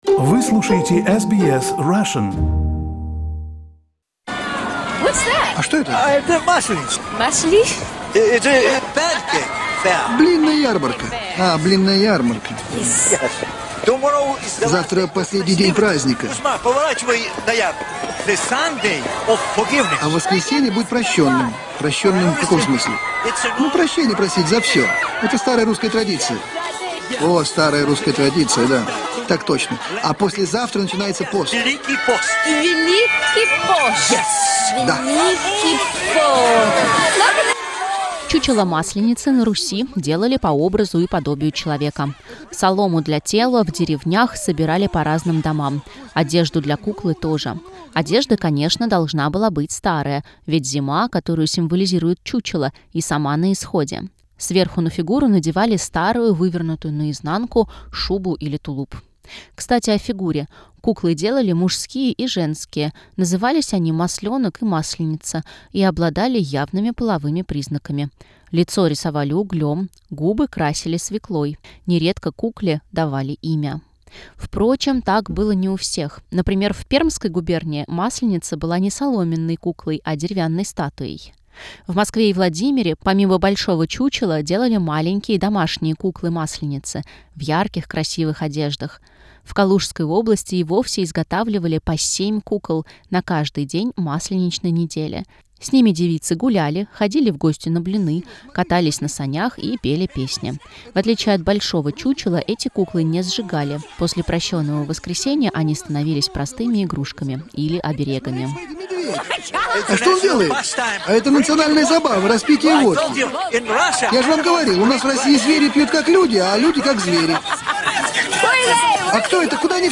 В сюжете использованы фрагменты из фильма "Сибирский цирюльник" Никиты Михалкова и мультфильма "Ишь ты, Масленица!" Роберта Саакянца.